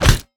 FleshWeaponHit1.wav